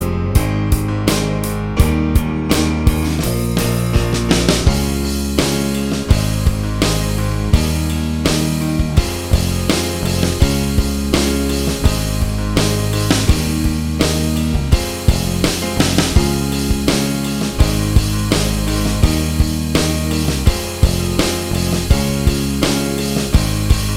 Minus Guitars With Backing Vocals Rock 4:21 Buy £1.50